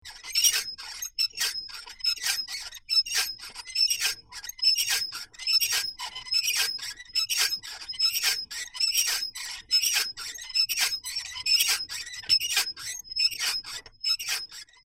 Звуки мясорубки
Звук ржавой ручной мясорубки